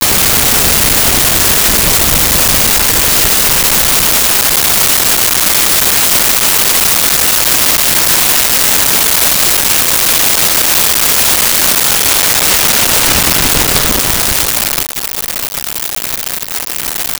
Explosion Large 2
Explosion Large_2.wav